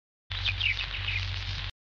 This work, premiered as part of the 'Anti-Parallel' Egenis show 2007, consists of 208 (4x52) discrete, short sound recordings derived from one side of a 78rpm gramophone record entitled 'Dawn In An Old World Garden' (HMV B2469); these sound recordings (or 'Sons') are then selected and played via the Parallel Music method.
• A or 'Aggregate' category, contains Sons which have been made from the birdsong recording and its attendant 'scratches'  (